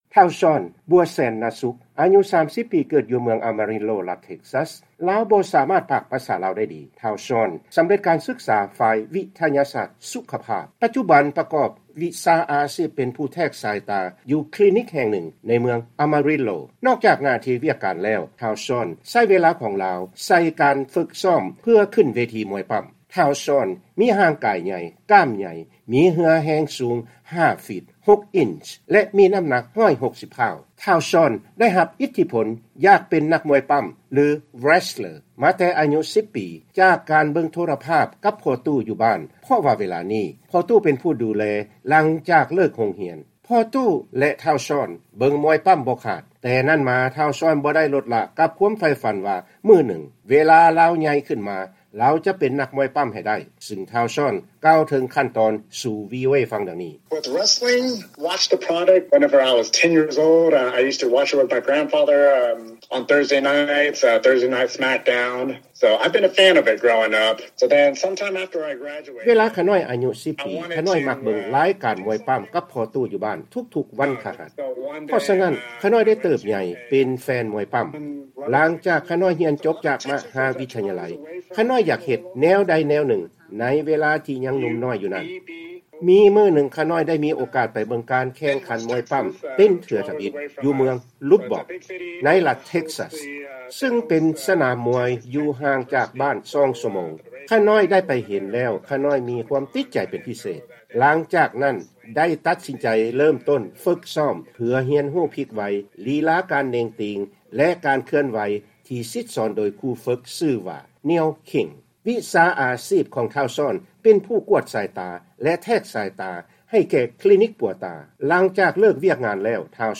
ວີໂອເອລາວ ສຳພາດ